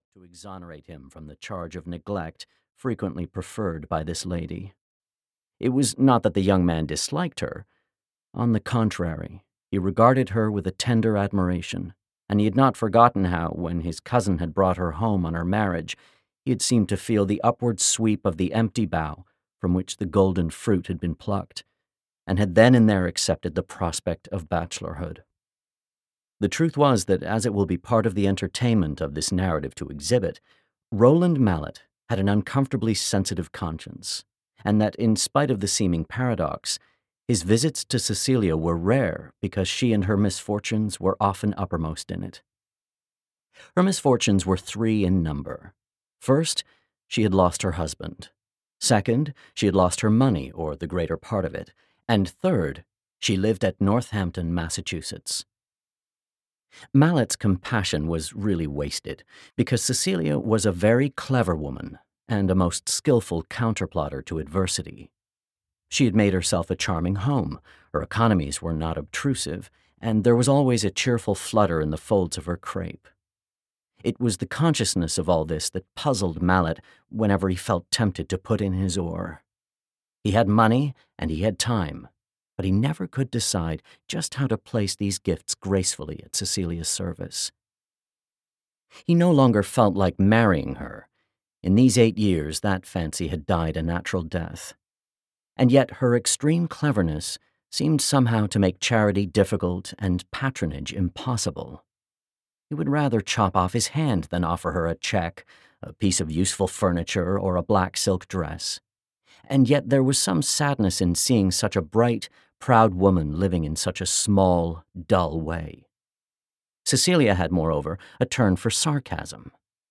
Roderick Hudson (EN) audiokniha
Ukázka z knihy